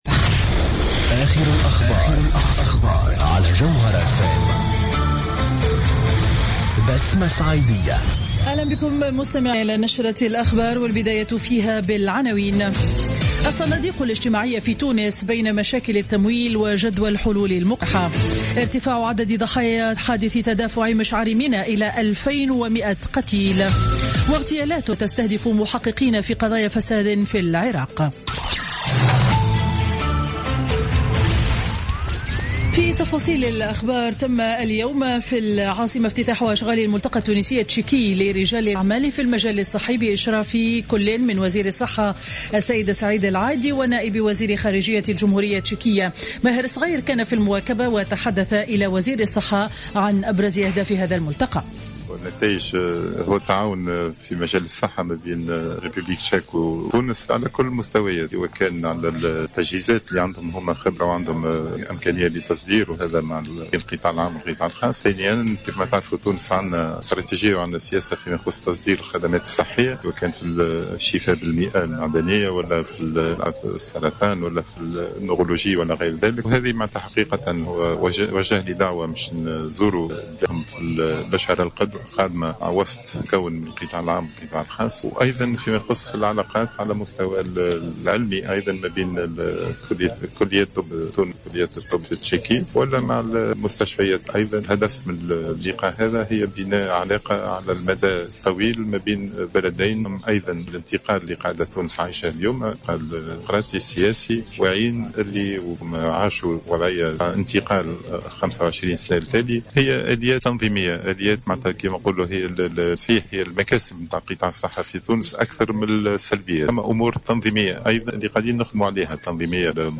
نشرة أخبار منتصف النهار ليوم الخميس 22 أكتوبر 2015